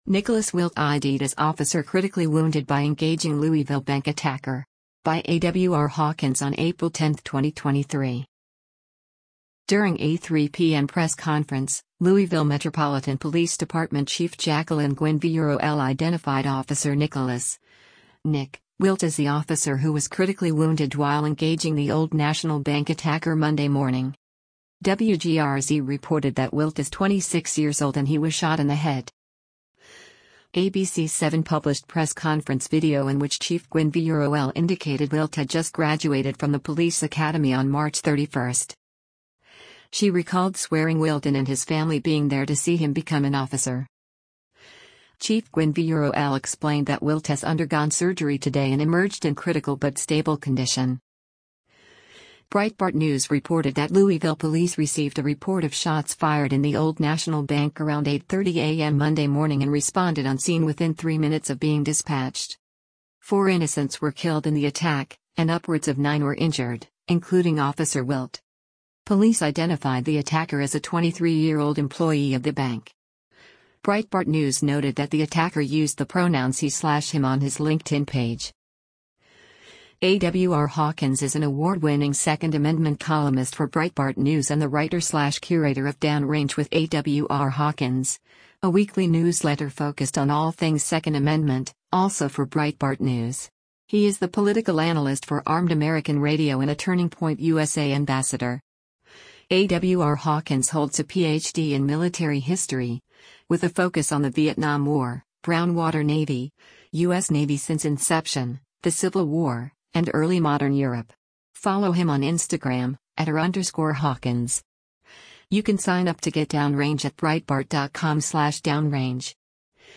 Video Source: Credit: Louisville Metro Police Department / LOCAL NEWS X /TMX